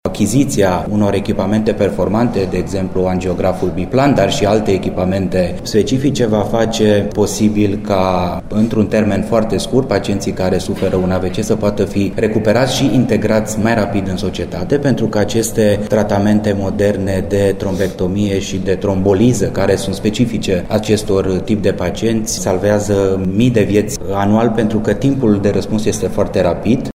Ministrul Sănătății, Alexandru Rogobete, a declarat că prin această investiție, instituția va avea, practic, un centru de excelență în tratarea pacienților care au suferit un accident vascular cerebral.